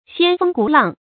掀風鼓浪 注音： ㄒㄧㄢ ㄈㄥ ㄍㄨˇ ㄌㄤˋ 讀音讀法： 意思解釋： 比喻煽動情緒，挑起事端。